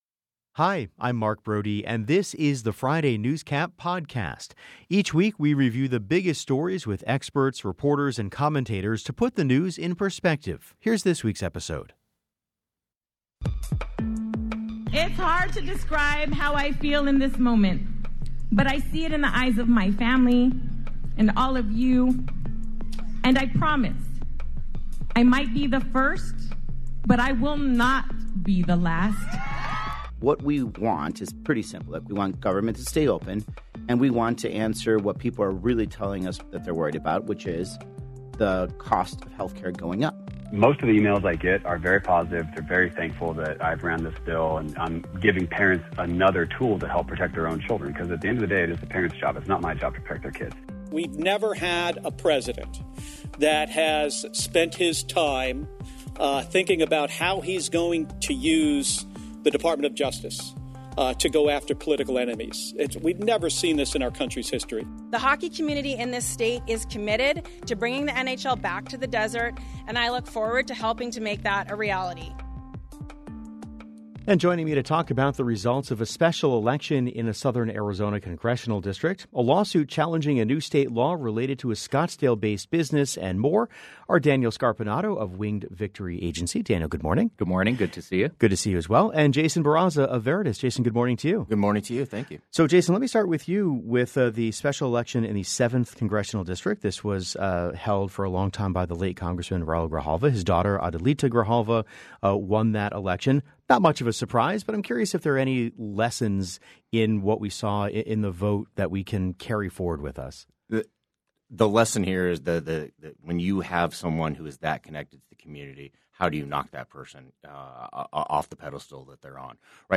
The Show's Friday NewsCap is a weekly review of the biggest stories with experts, reporters and commentators to put the news in perspective.